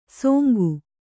♪ sōngu